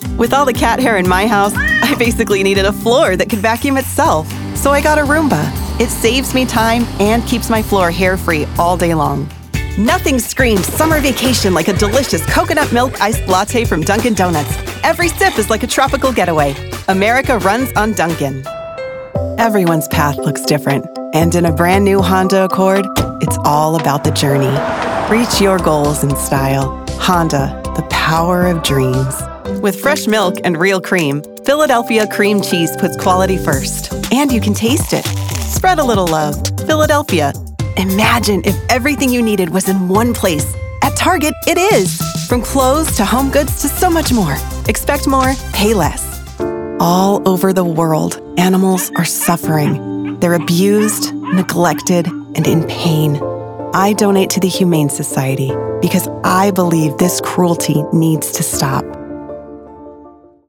Commercial Demo
English - Midwestern U.S. English
English - Western U.S. English
Young Adult
Middle Aged